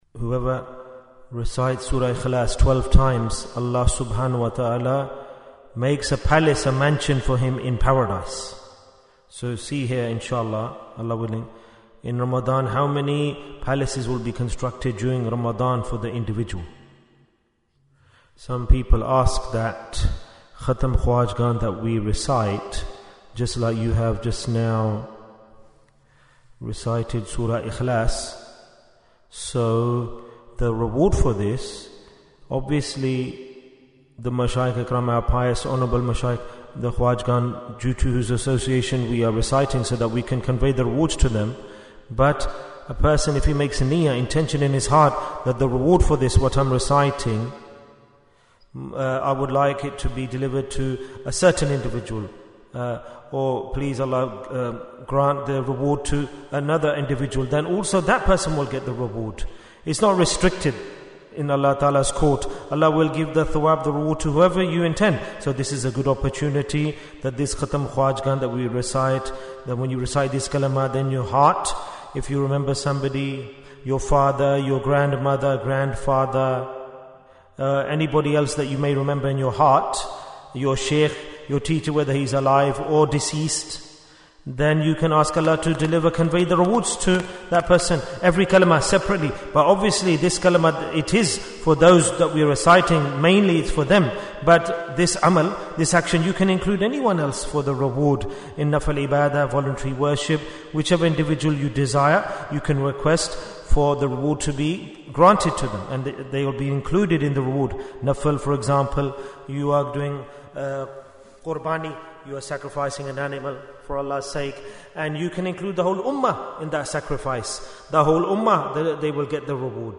Jewels of Ramadhan 2025 - Episode 10 Bayan, 23 minutes9th March, 2025